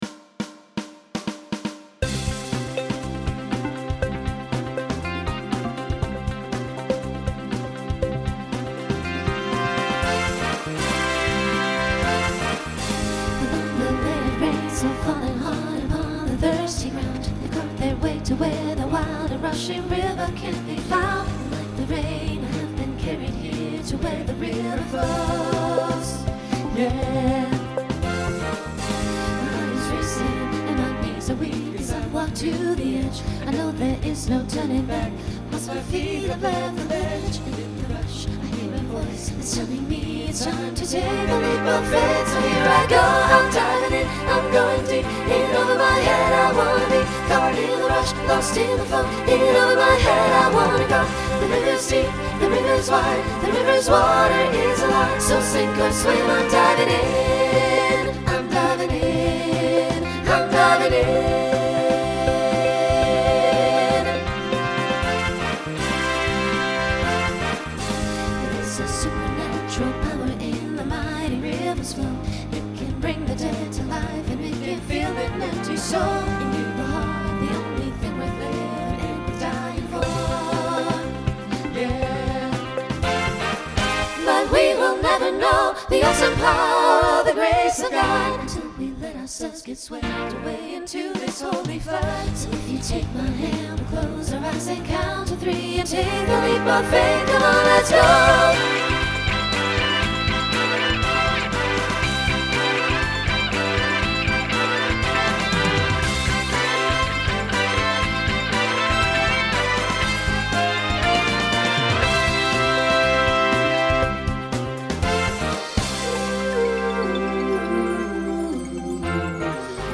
SATB Instrumental combo
Pop/Dance , Rock
Mid-tempo